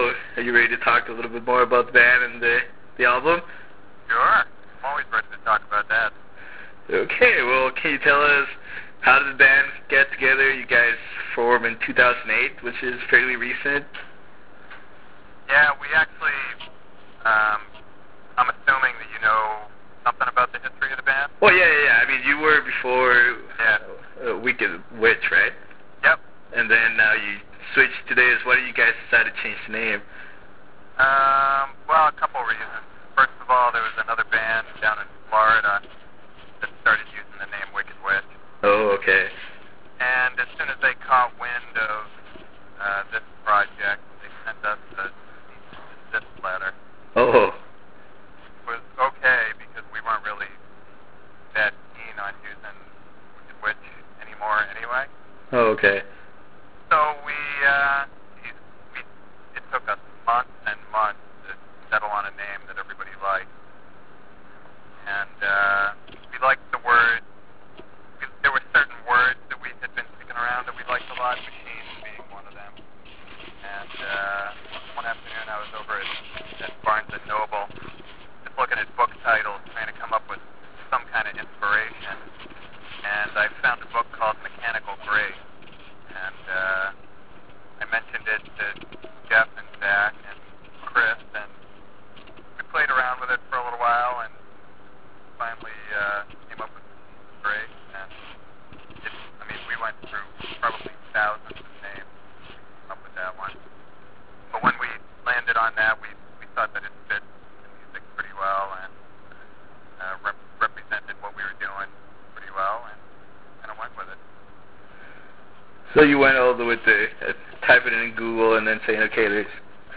Interview with Machines Of Grace
We talked about the release of "Machines Of Grace", the band's enigmatic name, and future plans for the band. Click HERE to listen to this 20+ minute interview, or select Save As and take it with you.